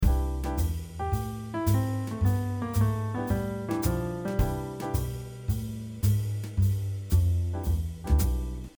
5. Using chromatic notes
Check out this example to hear how a chromatic note is being used in measure 2:
Using-chromatic-notes.mp3